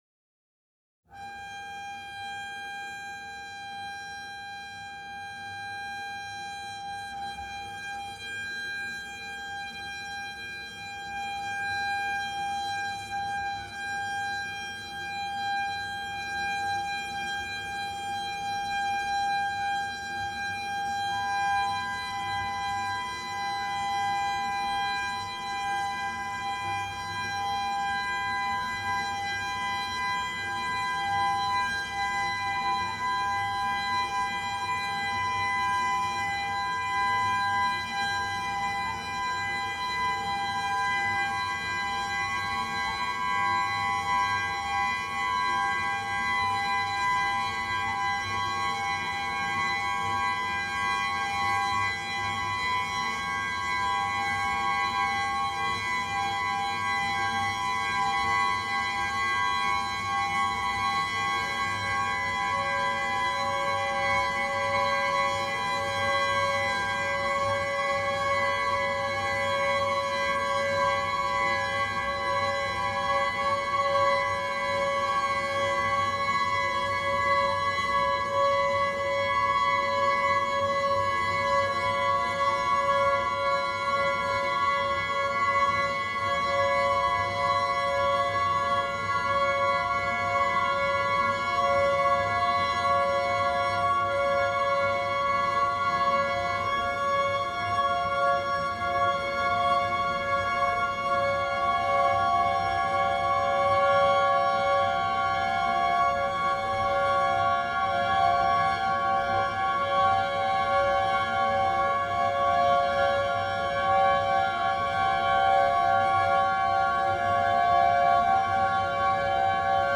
International Publisher and label for New experimental Music
double bass
three double bass quartets